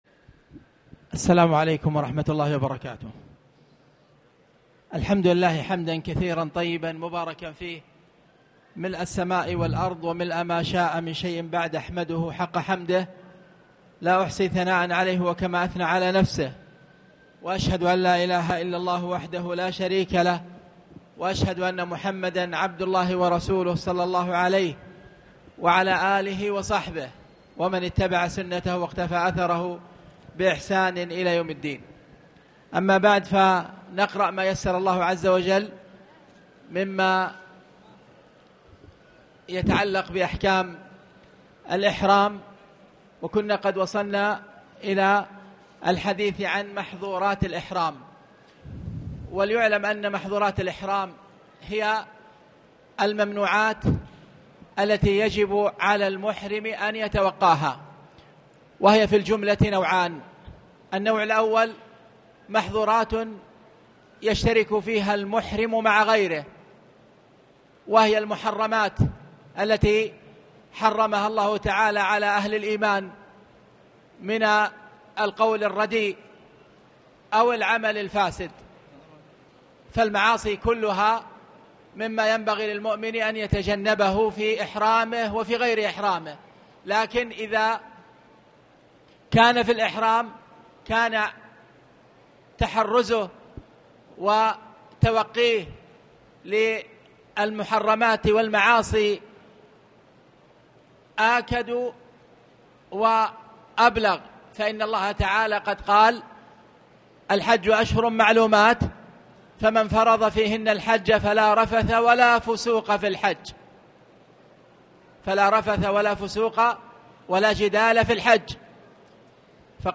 تاريخ النشر ٢٨ شوال ١٤٣٨ هـ المكان: المسجد الحرام الشيخ